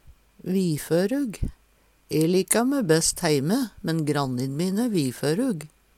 viførug - Numedalsmål (en-US)